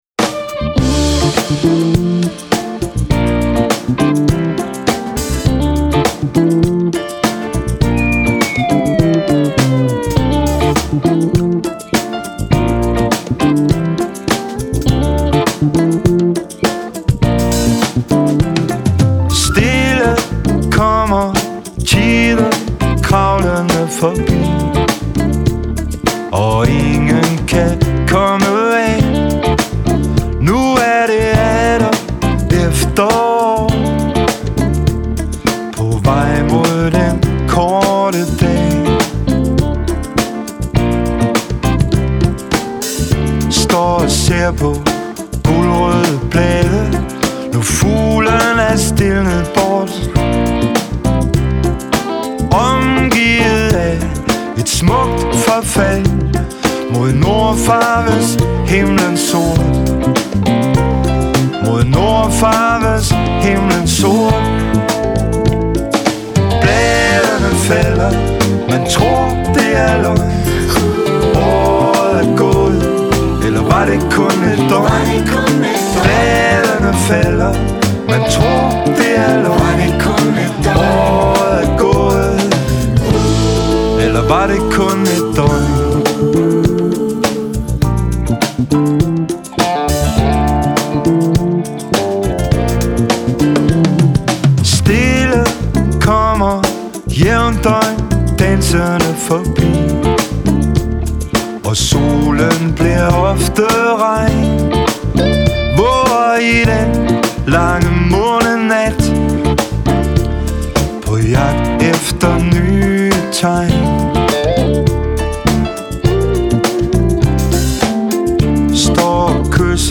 Indspillet i " Rythm Africa" studiet Accra. Ghana.